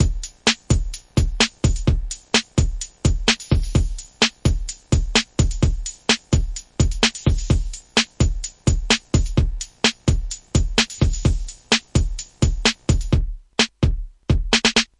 循环播放节拍和声音 " 128fm节拍
描述：128bpm鼓（fm声音）
Tag: FM fmdrums